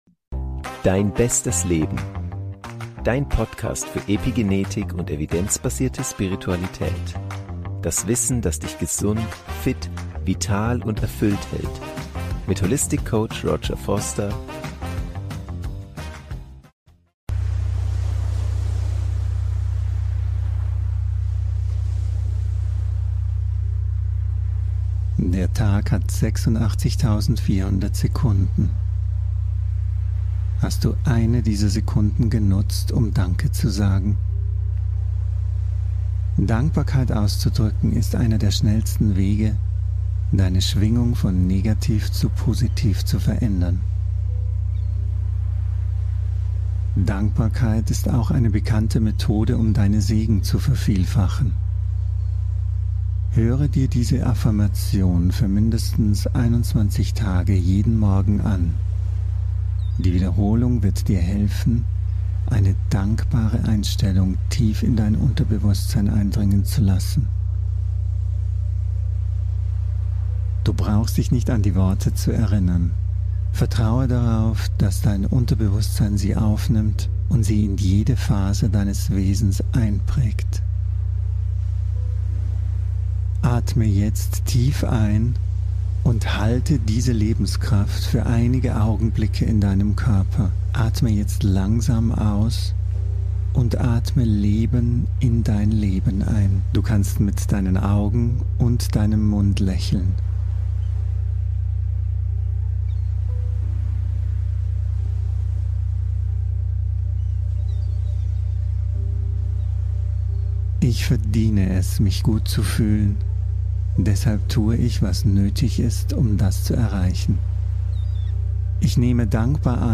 Die Meditation ist mit binauralen Beats (Gamma Wellen) mit 30 Hz unterlegt.